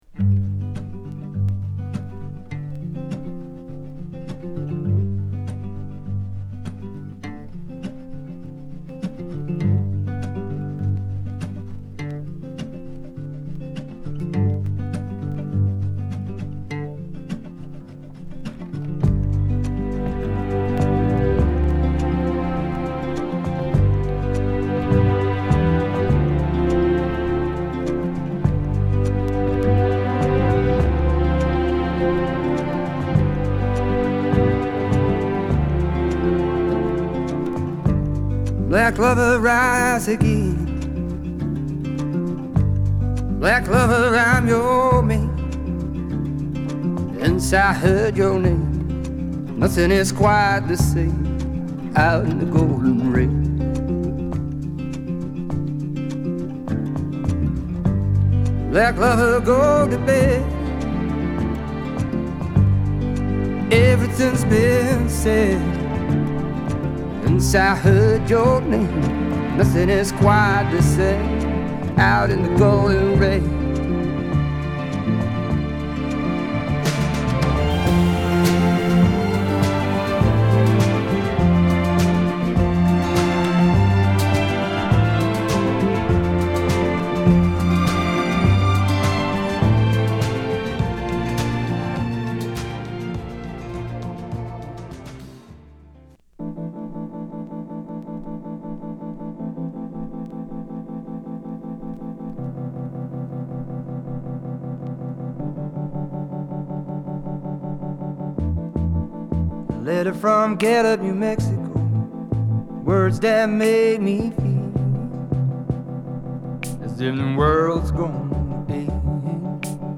ベルギーのシンガー・ソングライター